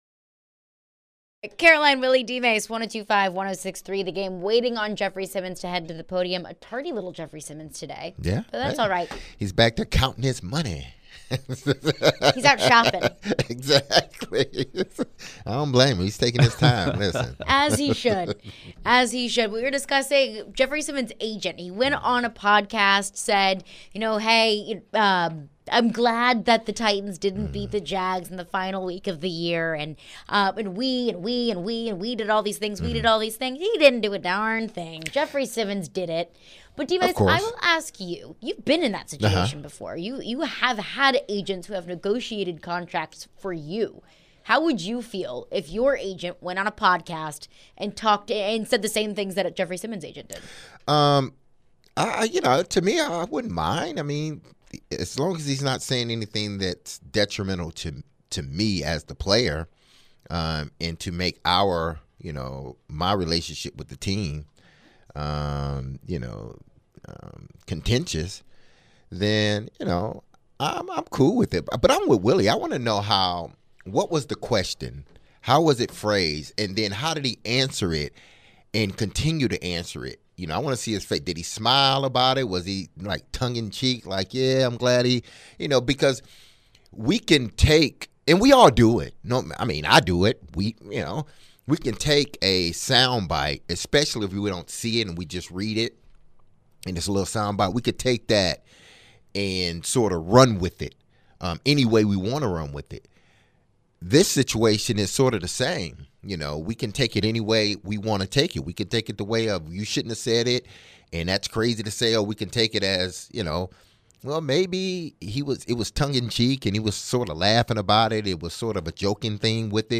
Catch the full press conference with Jeffery Simmons here after he signed his new contrast with the Titans.